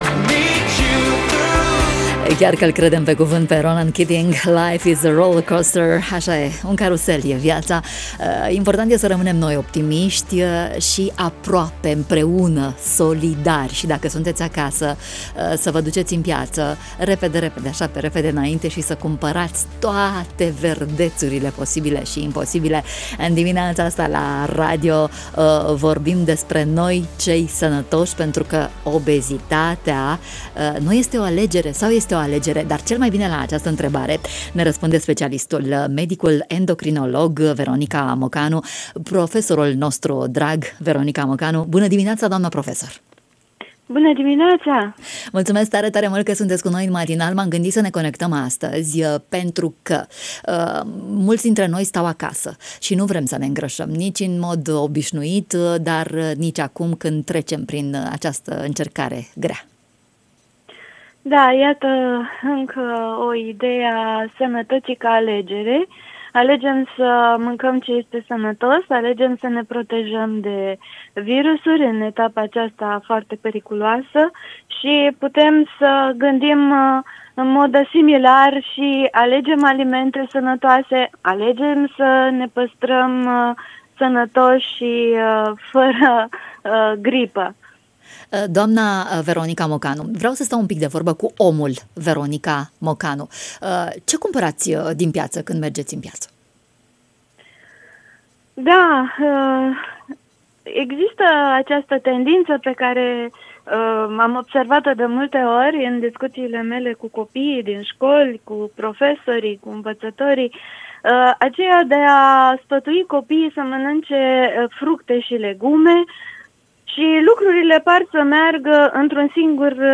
în matinalul Radio România Iaşi.